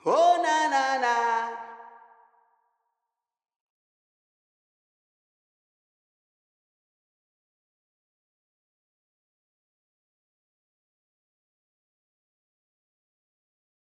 Vocal Zion 1.wav